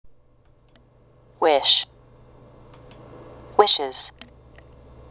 ５】esをつけて「i:z(イーズ)」と発音する動詞 　 (chやs/shの音で終わる単語)